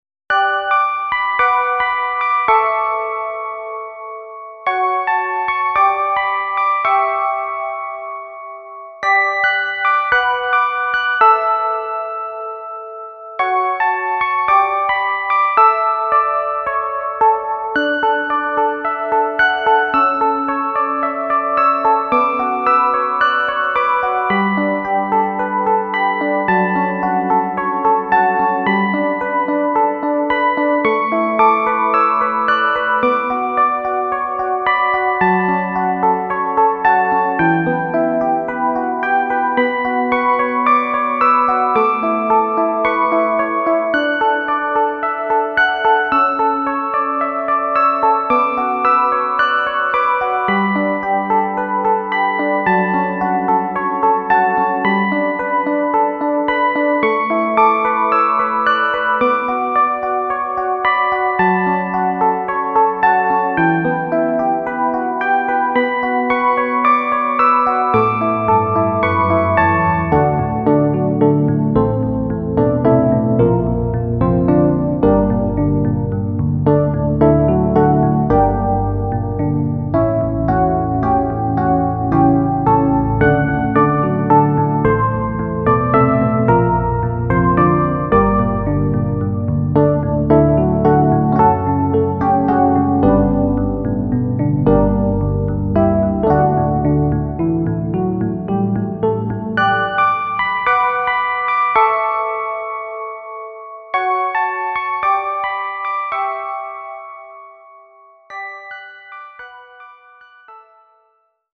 エレピによる爽やかな曲。中盤から海に潜ったかのような壮大さも感じられる